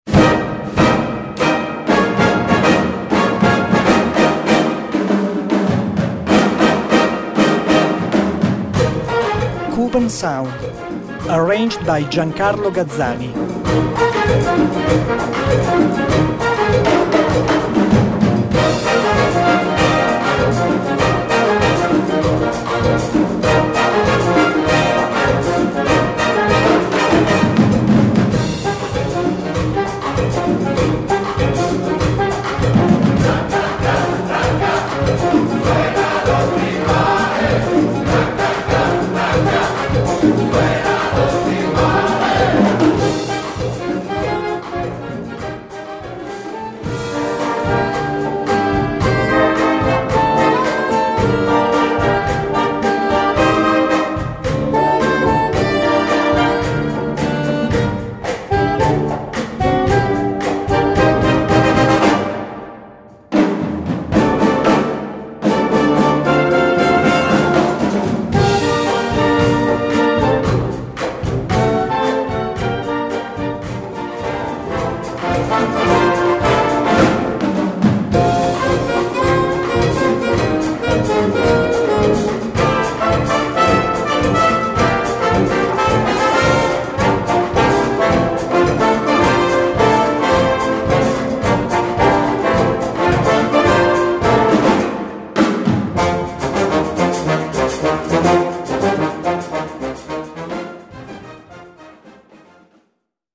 Gattung: Lateinamerikanisches Medley
Besetzung: Blasorchester
lateinamerikanische Medley